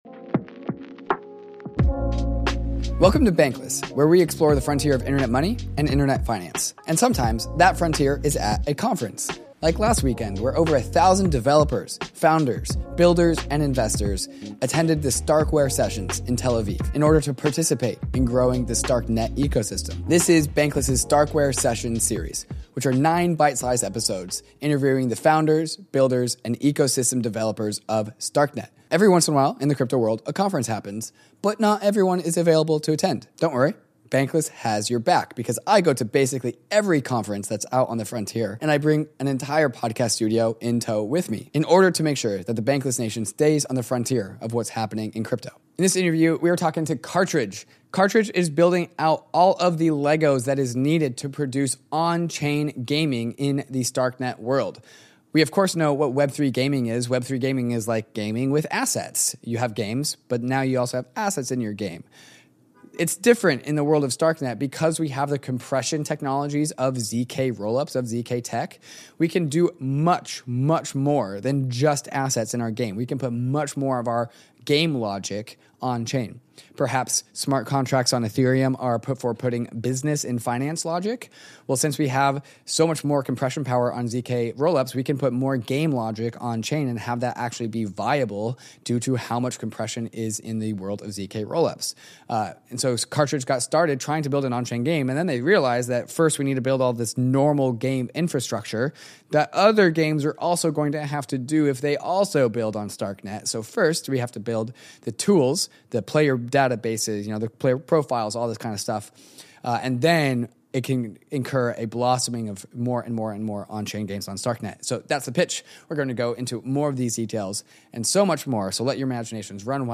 Sometimes, the frontier is at a crypto conference. We’re returning from our adventures in Tel Aviv with nine exclusive interviews with some of the key players in the StarkNet space.